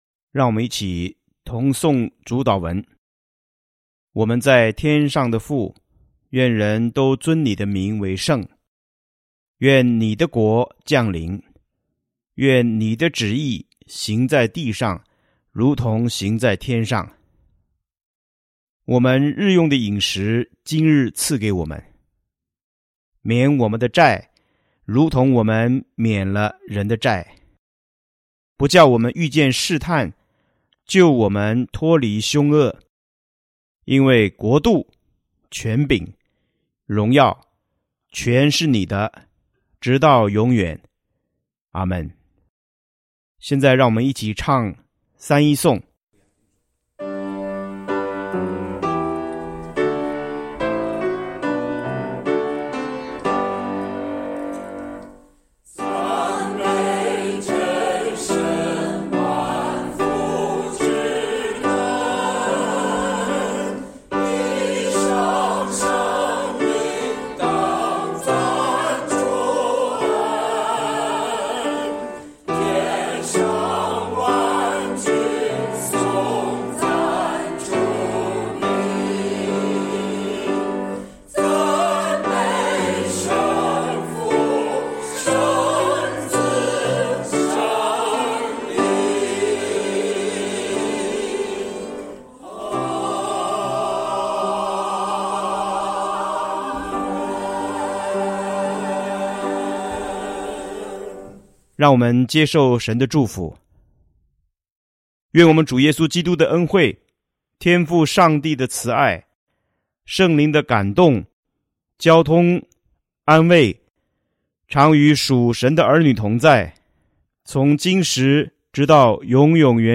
8.同诵主祷文